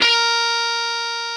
NoteA#4.wav